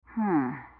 hmm1.wav